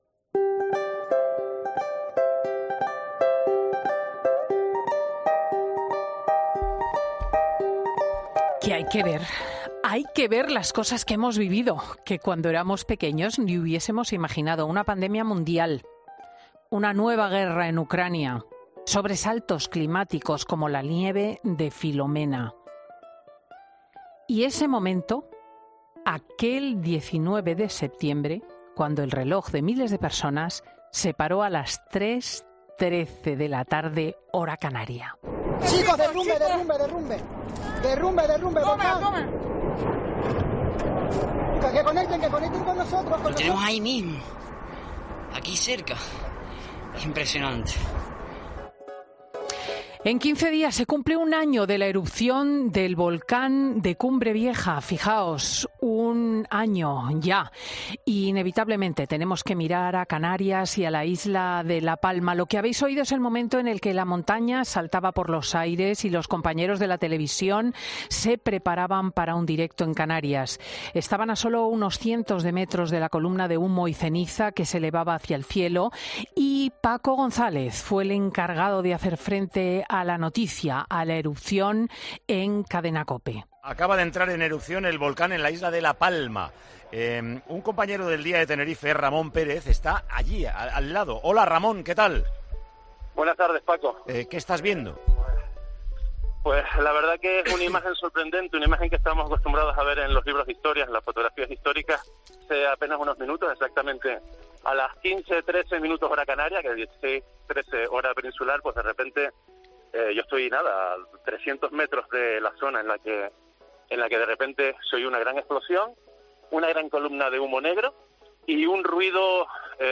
para reportajes y entrevistas en profundidad